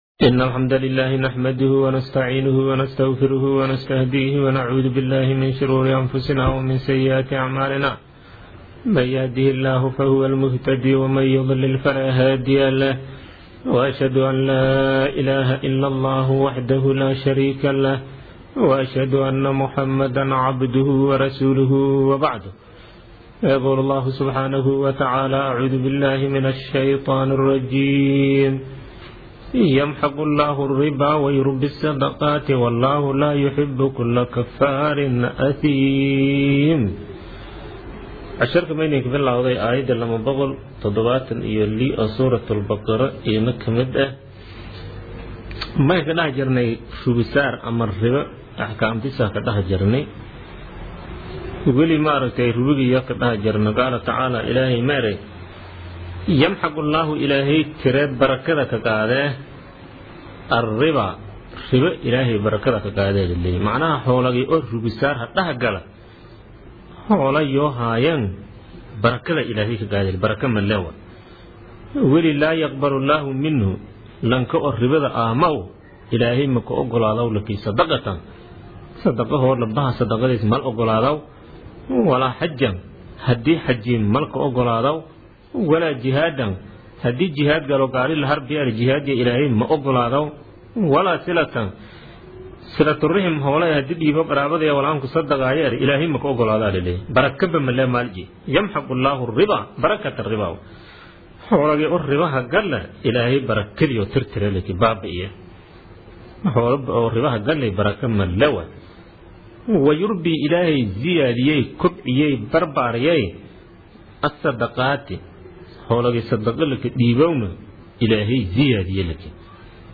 Casharka Tafsiirka Maay 37aad